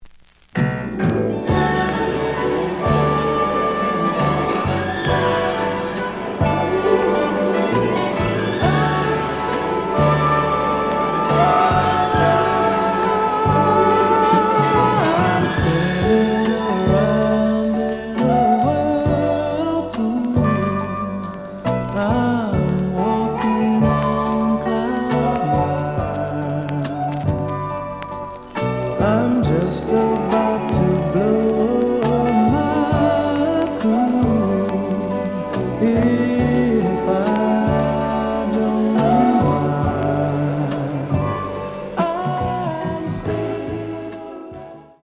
The audio sample is recorded from the actual item.
●Genre: Soul, 70's Soul
Slight noise on A side.